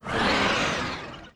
Monster_09_Attack.wav